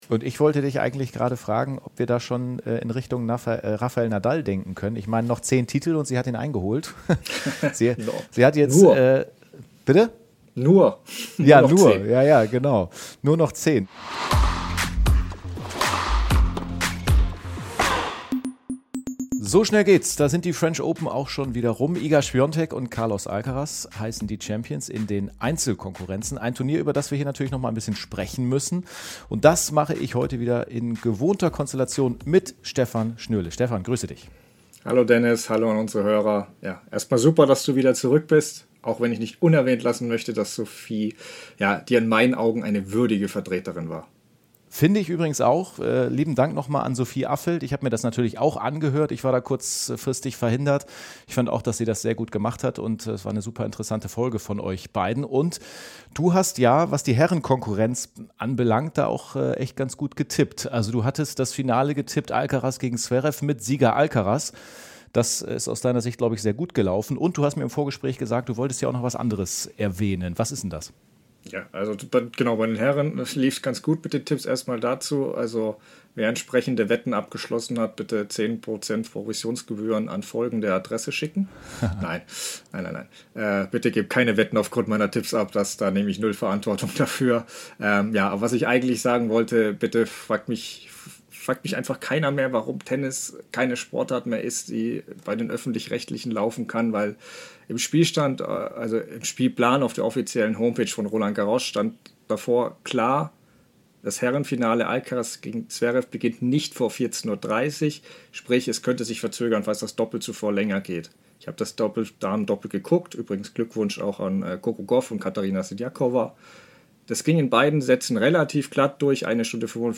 Dabei wird auch eine umstrittene Entscheidung diskutiert. Nachdem auch Zverev selbst zu Wort kommt, rückt seine Zukunft in den Mittelpunkt.